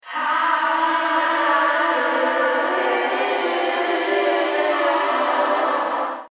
Im Klangbeispiel hörst Du nun ausschließlich den vierten Effektkanal. Das Ergebnis zeigt jetzt deutlich mehr Raum und klingt schon sehr professionell.
Reverb Klangbeispiel: Nur der 4. Effektkanal